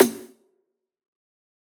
taiko-soft-hitclap.ogg